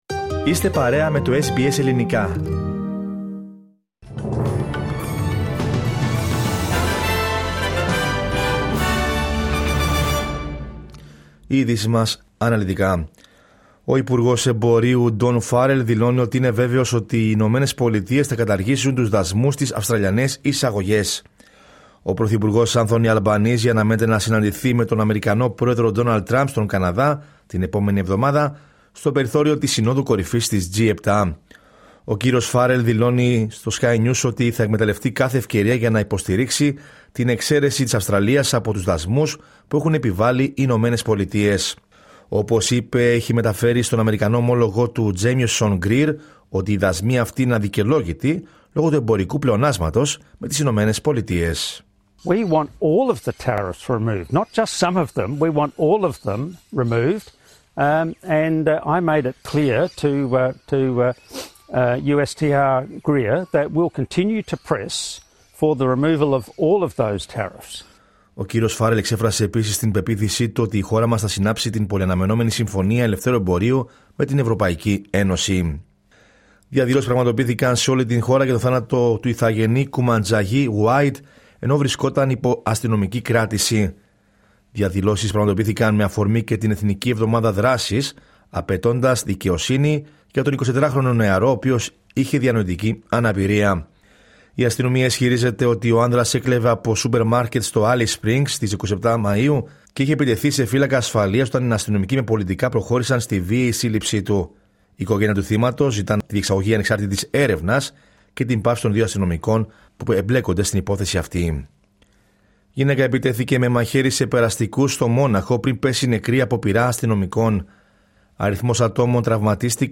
Δελτίο Ειδήσεων Κυριακή 8 Ιουνίου 2025